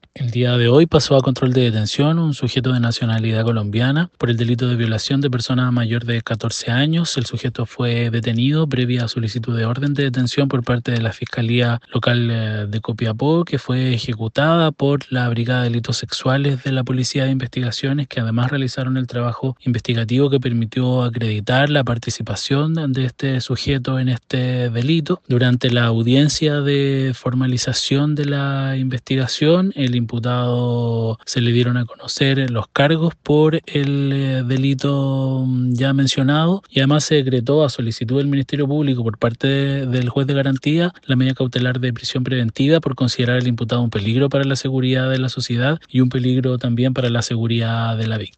AUDIO-FISCAL-GUILLERMO-ZARATE.mp3